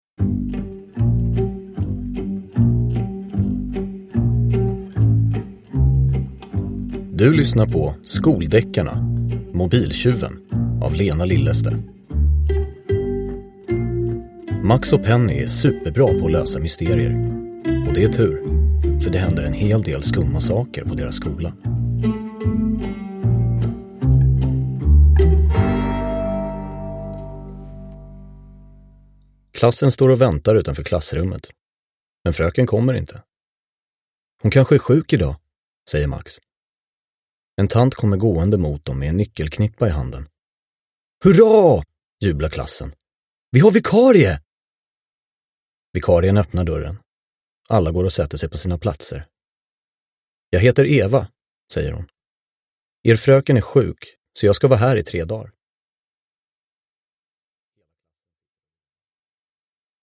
Mobil-tjuven – Ljudbok – Laddas ner
Uppläsare: Anastasios Soulis